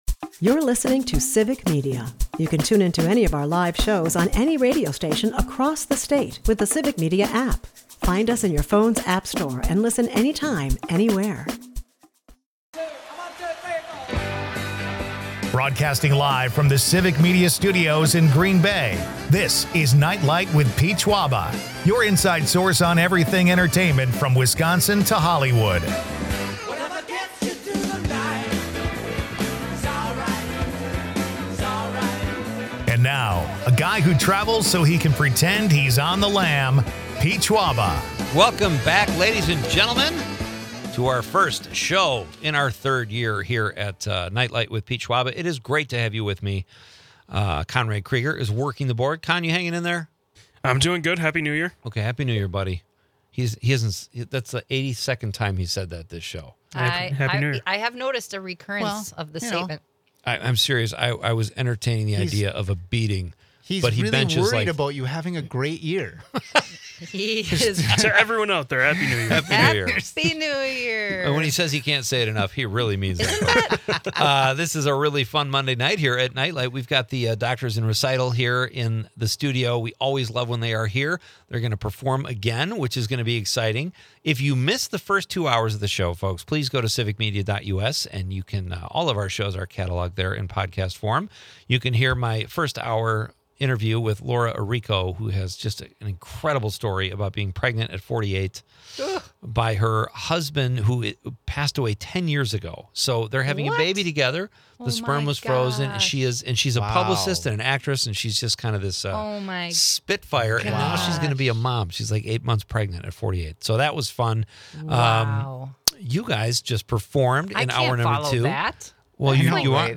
They delight with a live rendition of 'Cecilia' and discuss the show's 20-year legacy supporting local causes.